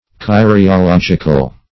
Kyriological \Kyr`i*o*log"ic*al\, a. [See Curiologic.]